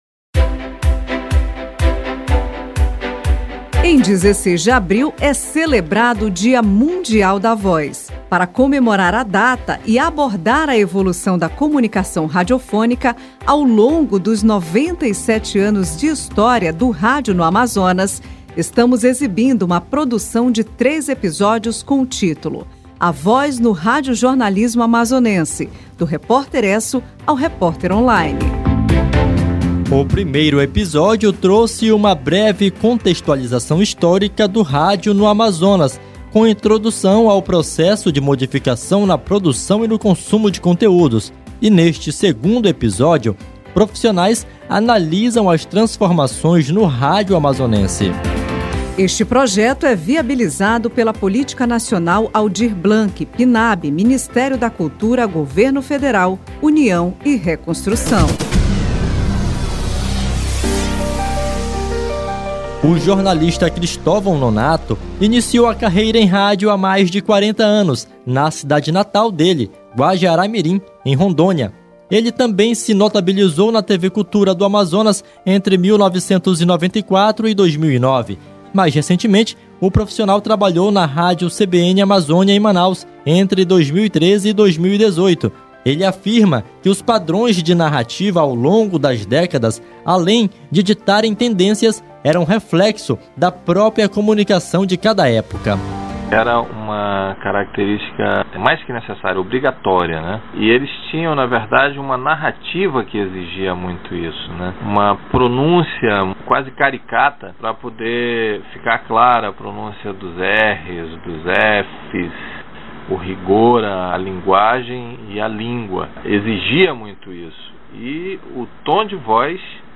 E, neste segundo capítulo, profissionais do rádio falaram sobre os 97 anos de transformações no Amazonas.